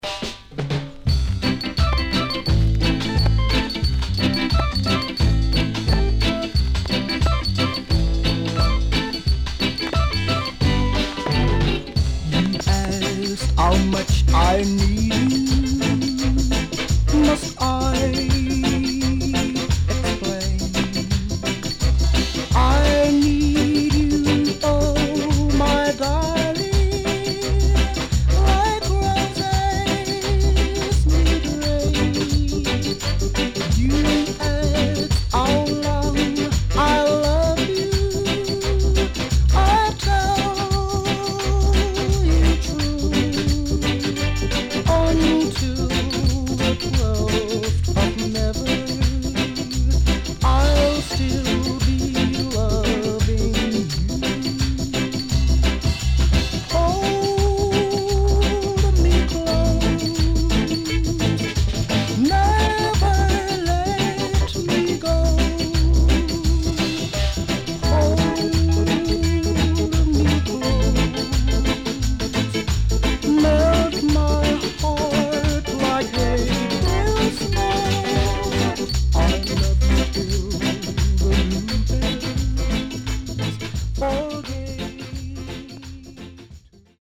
HOME > Back Order [VINTAGE 7inch]  >  EARLY REGGAE
CONDITION SIDE A:VG(OK)
SIDE A:所々チリノイズがあり、少しプチノイズ入ります。